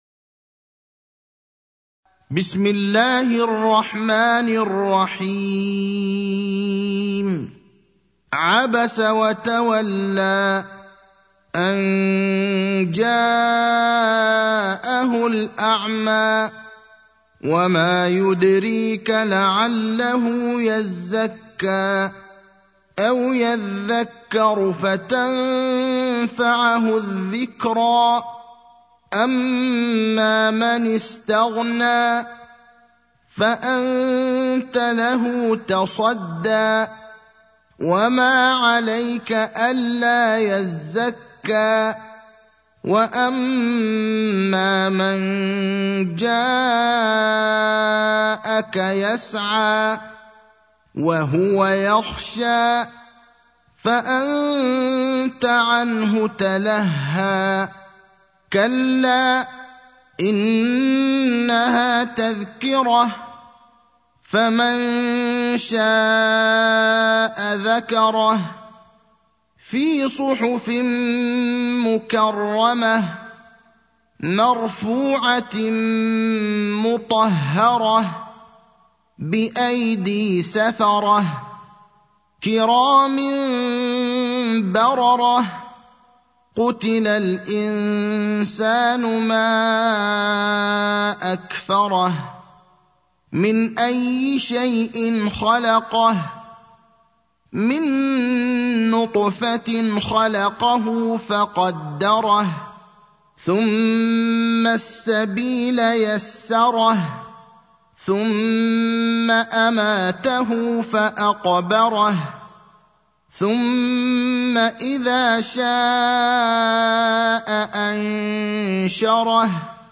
المكان: المسجد النبوي الشيخ: فضيلة الشيخ إبراهيم الأخضر فضيلة الشيخ إبراهيم الأخضر عبس (80)